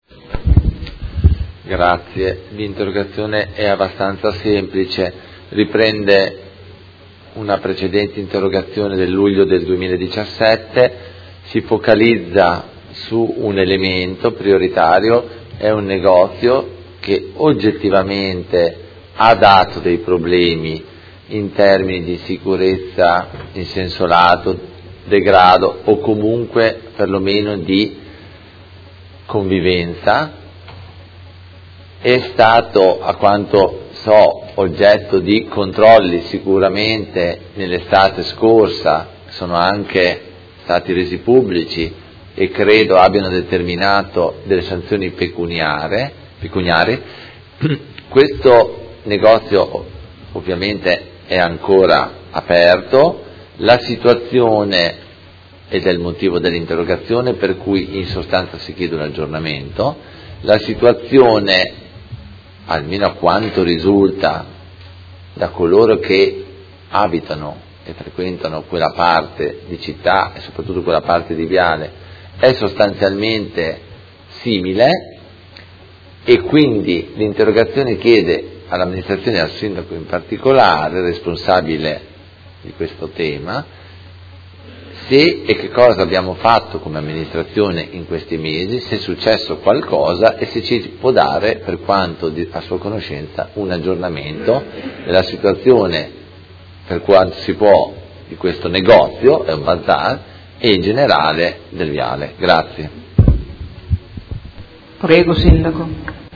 Seduta del 5/04/2018. Interrogazione del Consigliere Carpentieri (PD) avente per oggetto: Sicurezza in città con particolare riferimento alla zona di Viale Gramsci